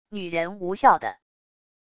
女人无效的wav音频